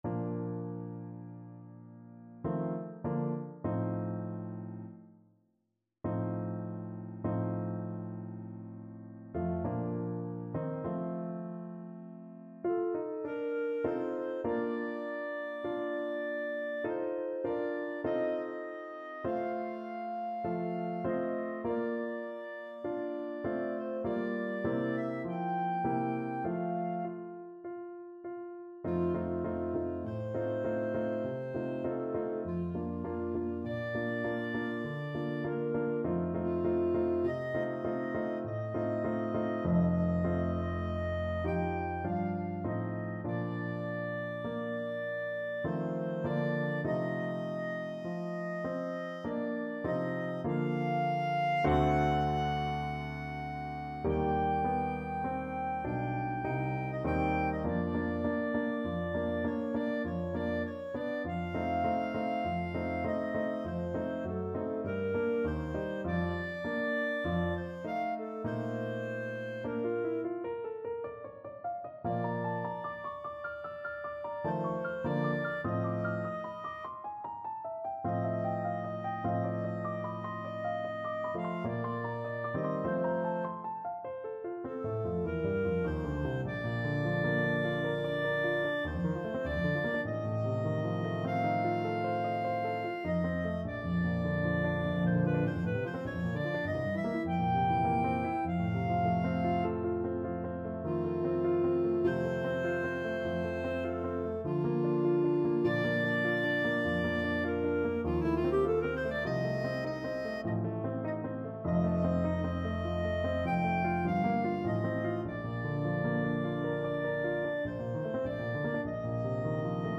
Clarinet version
3/4 (View more 3/4 Music)
Largo
Classical (View more Classical Clarinet Music)